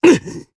Riheet-Vox_Attack3_jp.wav